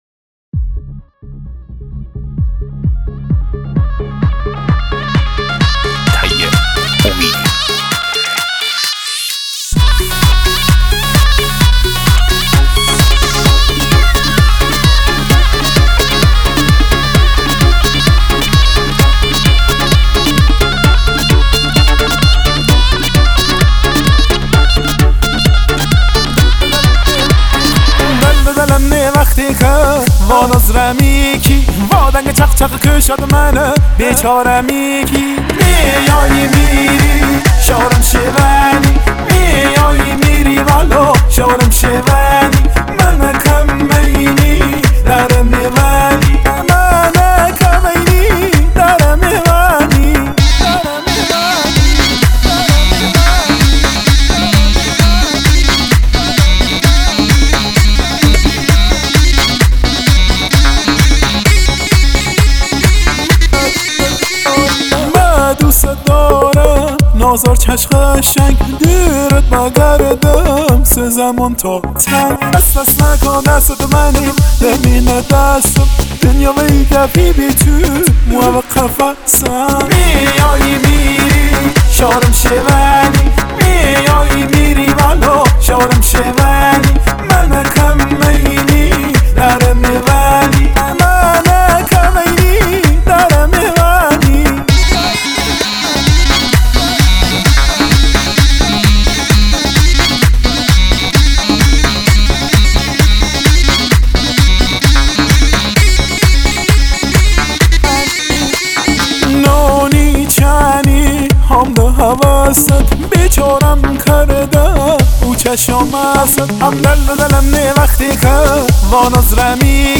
آهنگ لری شاد برای عروسی